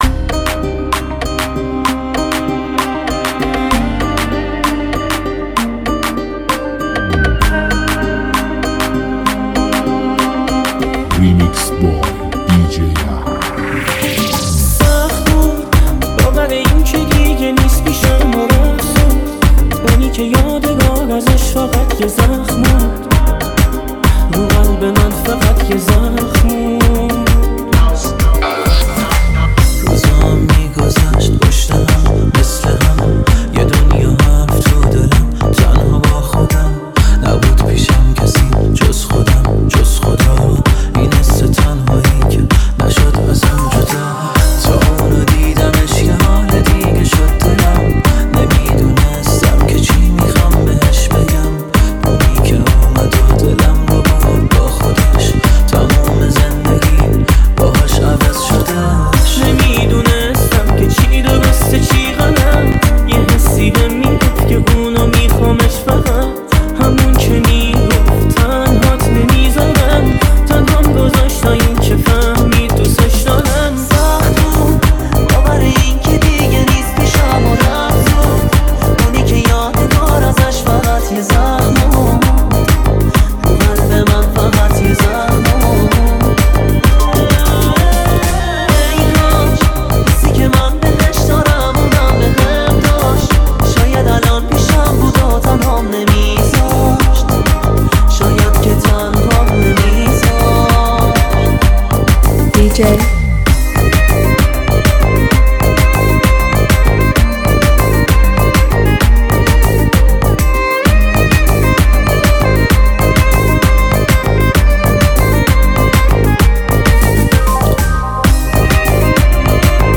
لذت بردن از موسیقی پرانرژی و بیس دار، هم‌اکنون در سایت ما.